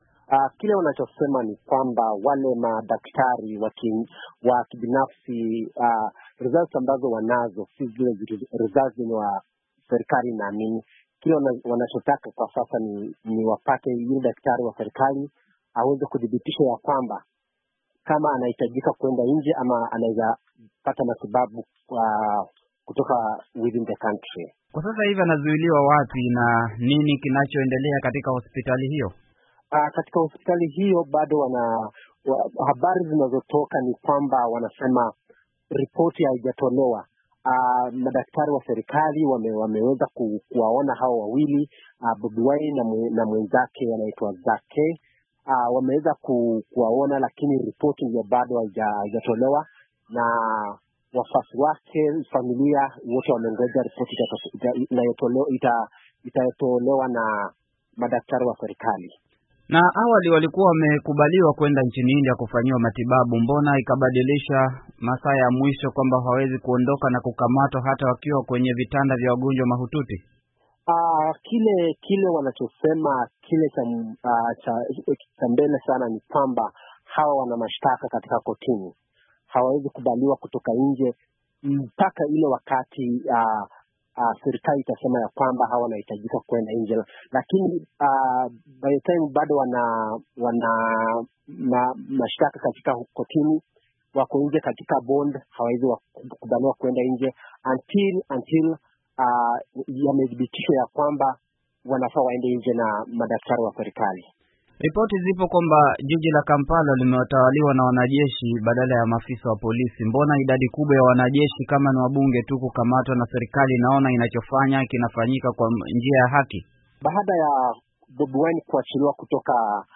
Mahojiano juu ya kukamatwa Bobi Wine, Uganda